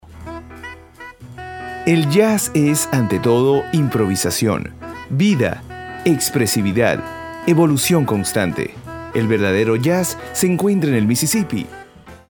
Poised, confident, with strong speaking voice as well as talent for on-air interviewing. With a uniquely refreshing and crisp tone of voice has lended his craft to international brands like: CosmĂ©ticos Rolda MBE Etc BaterĂas MAC Tanagua Panna TaxCare Orlando